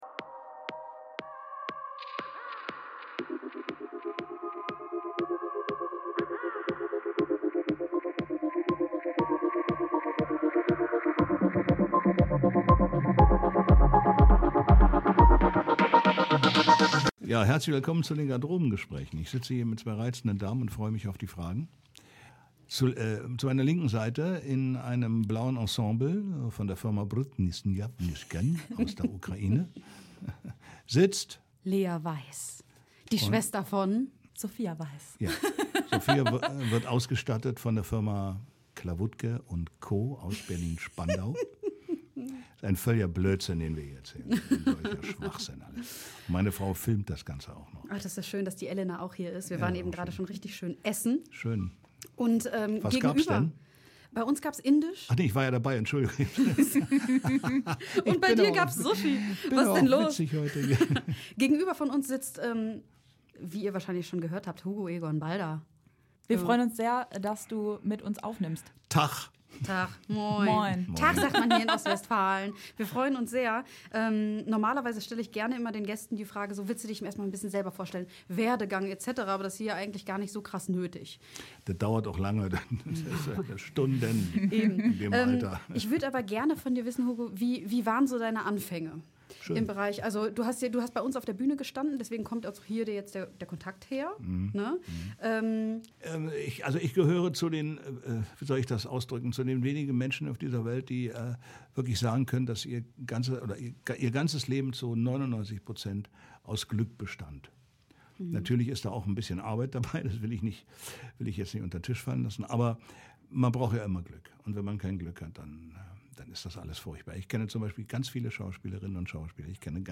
im Studio des Pioneers Club in Bielefeld